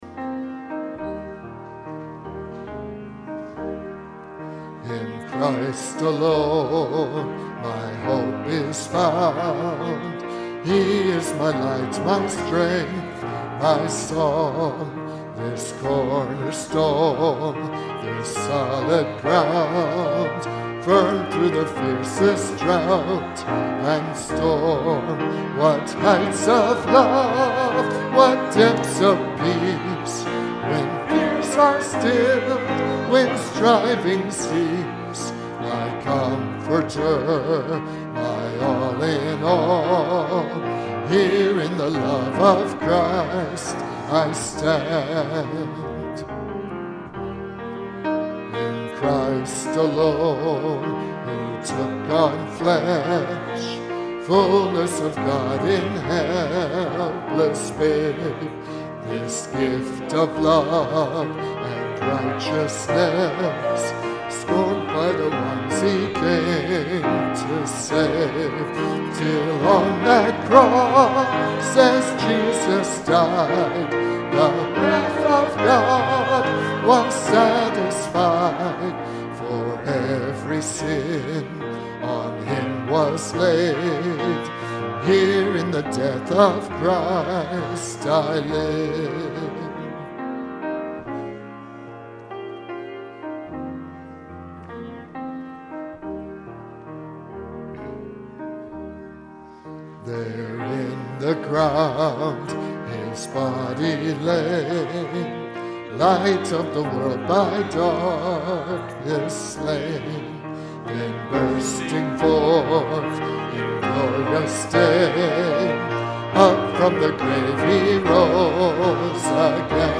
Bible Text: Psalm 23:1-6 | Preacher